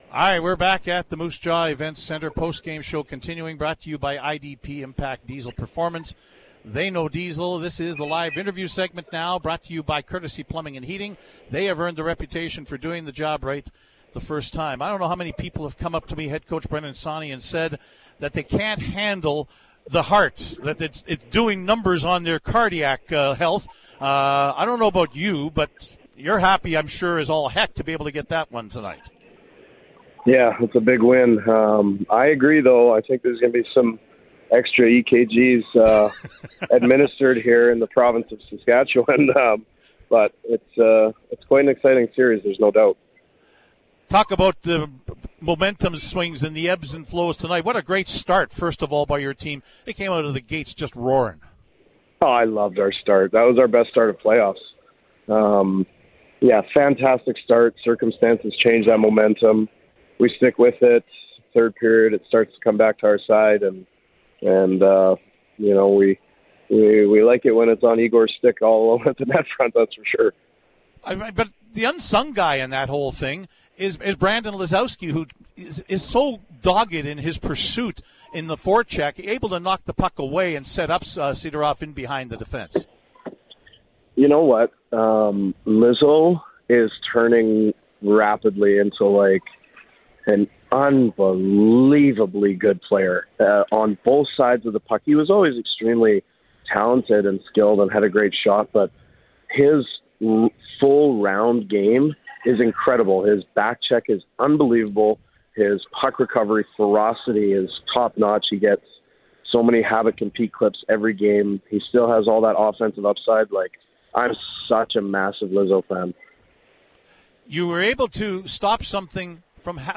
On CJWW’s Post-Game Show